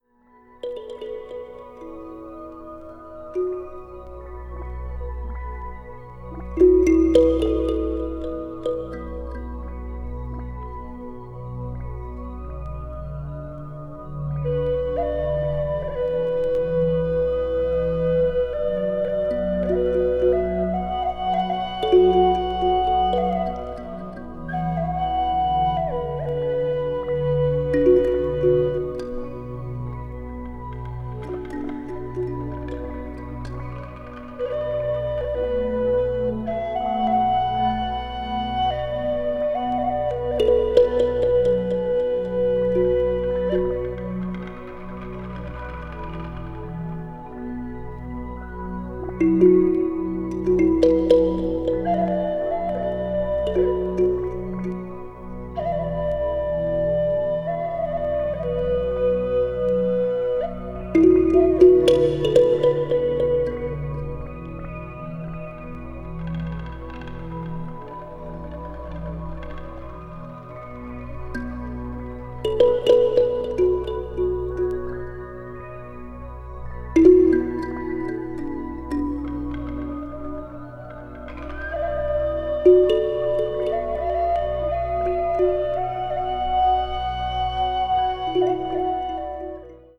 Chinesische Meditationsmusik
とても美しいサウンドです。
ambient   china   meditation   new age   world music